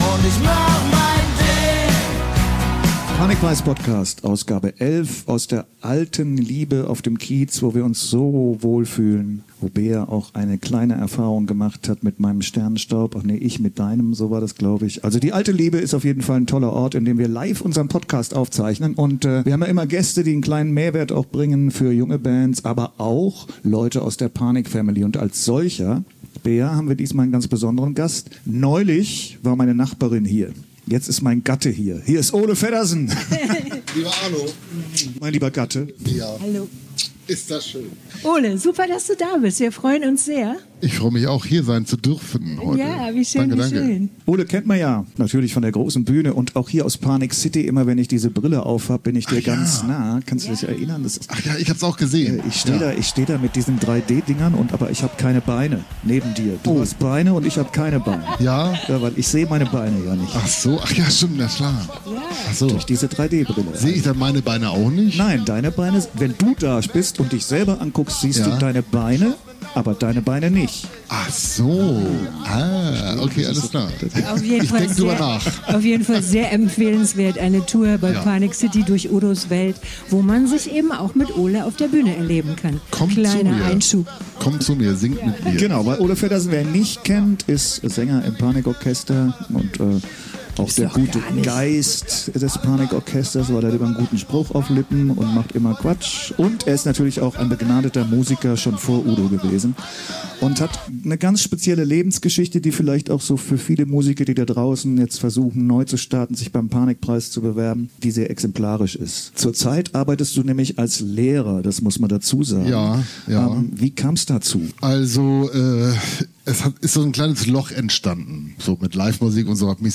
Da fliegen dann eine Menge Herzen durch den digitalen Äther Es war ein sehr schönes Gespräch.